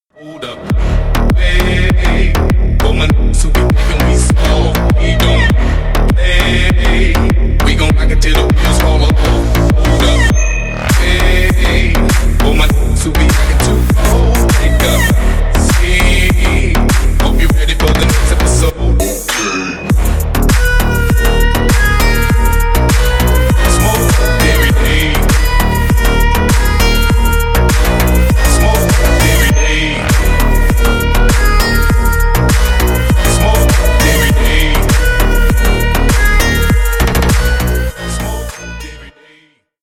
Ремикс # Рэп и Хип Хоп
громкие